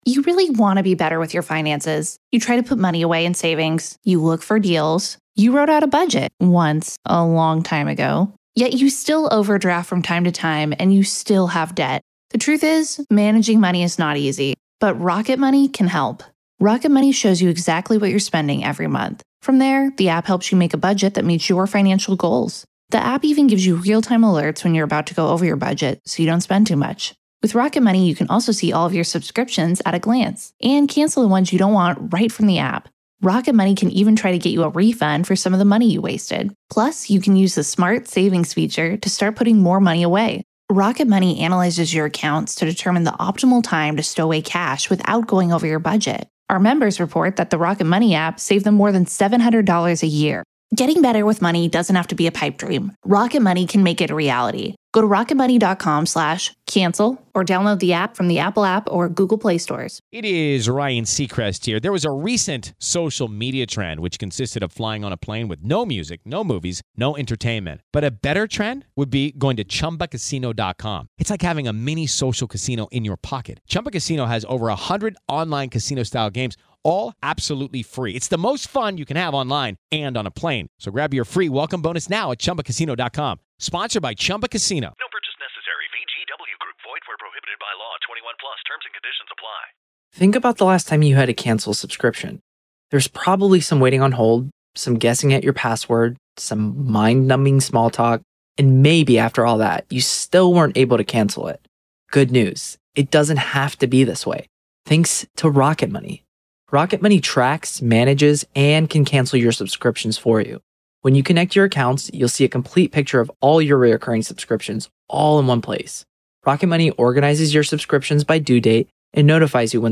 LIVE COURTROOM COVERAGE — NO COMMENTARY
This series provides unfiltered access to the testimony, exhibits, expert witnesses, and courtroom decisions as they happen. There is no editorializing, no added narration, and no commentary — just the court, the attorneys, the witnesses, and the judge.